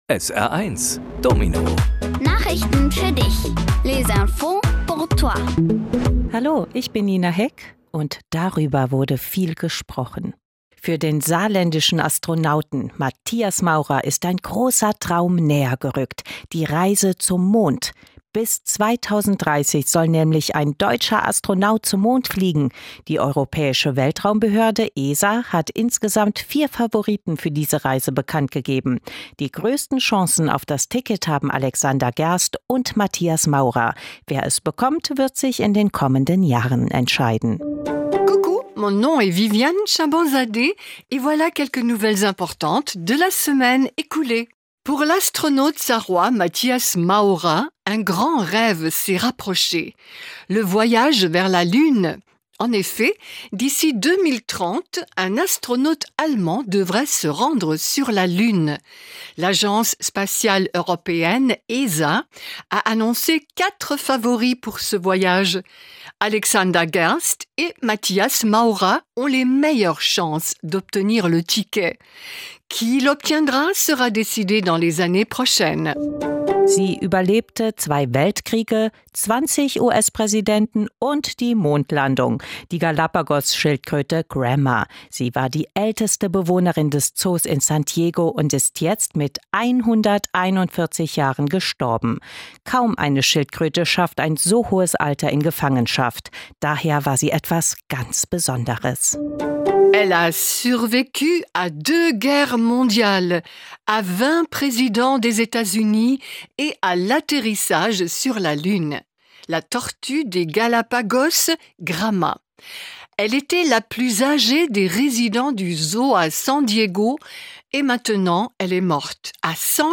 Kindgerechte Nachrichten auf Deutsch und Französisch:Hoffnung auf Mond-Ticket, Schildkröte 'Gramma' gestorben, Altersgrenze für Social Media, KI im Unterricht
Die wichtigsten Nachrichten der Woche kindgerecht aufbereitet auf Deutsch und Französisch